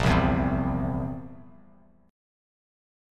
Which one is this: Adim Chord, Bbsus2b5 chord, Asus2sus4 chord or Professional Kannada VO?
Bbsus2b5 chord